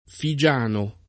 figiano [ fi J# no ] etn. (delle isole Figi)